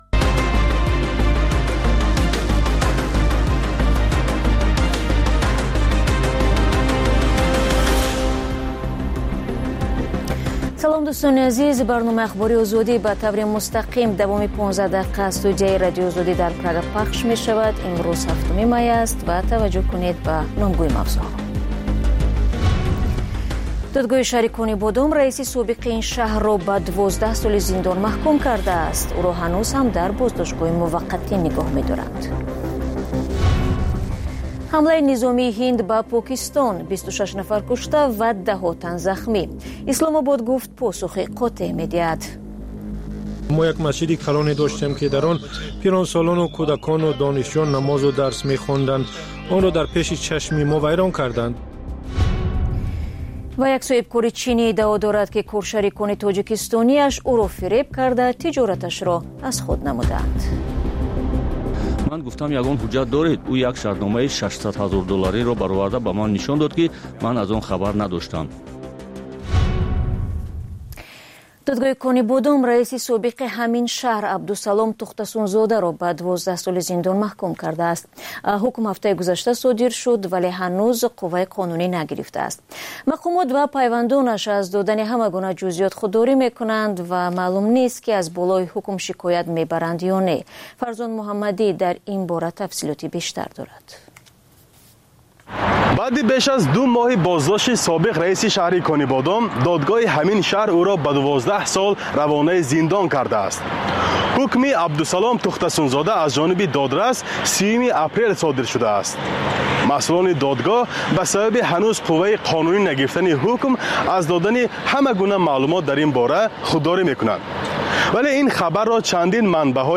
Маҷаллаи хабарӣ